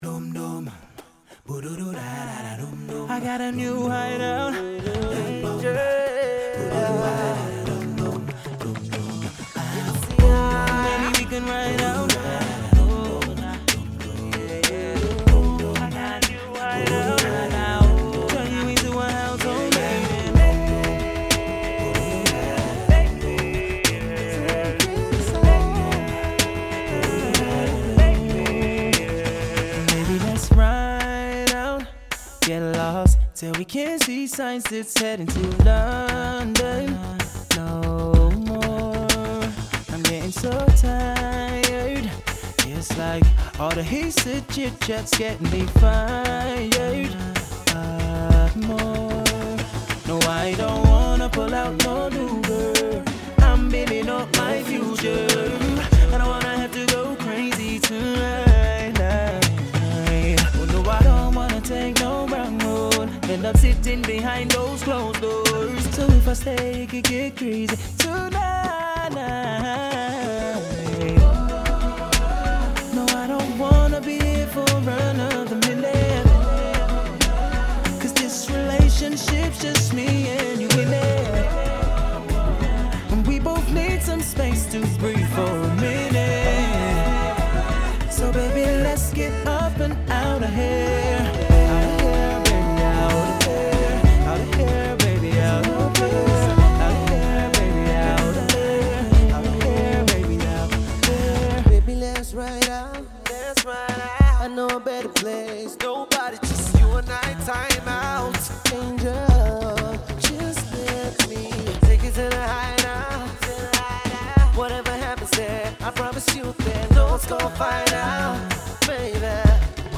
Who better than R&B turned Afro Pop star